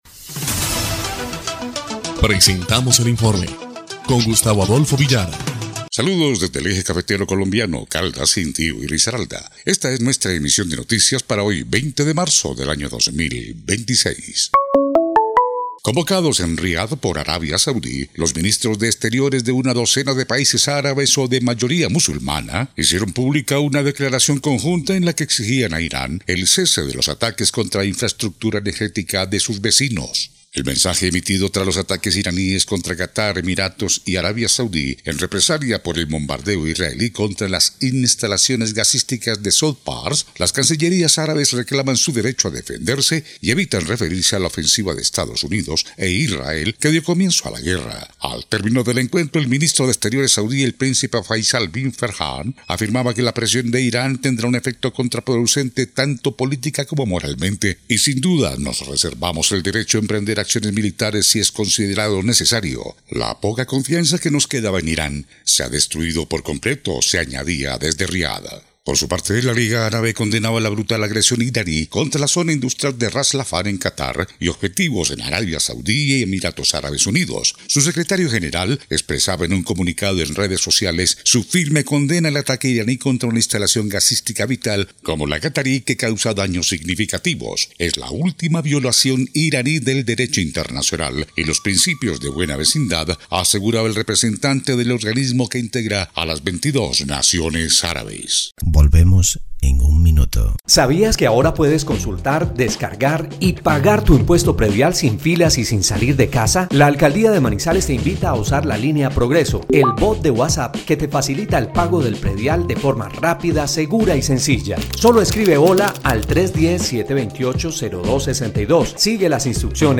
EL INFORME 2° Clip de Noticias del 20 de marzo de 2026